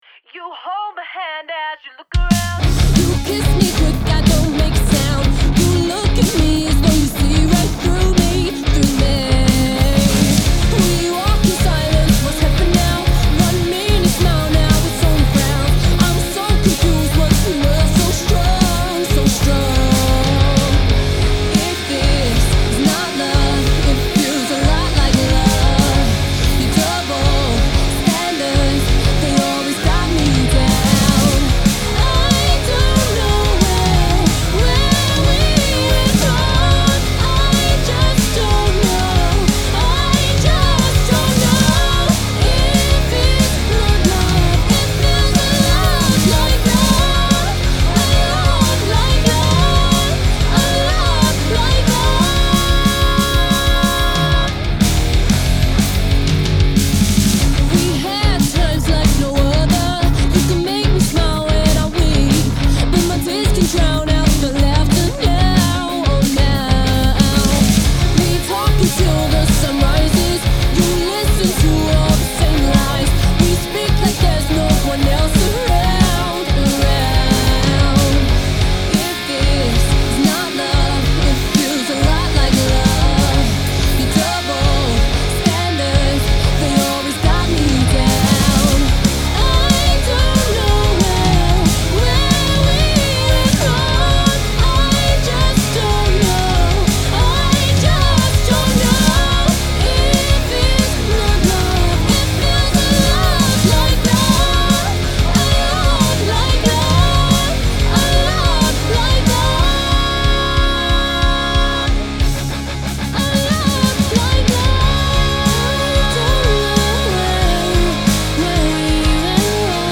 Рок_Сведение_Нужны мнения
Вот это было в процессе производства. У некоторых есть мнение, что голос отдельно от фанеры.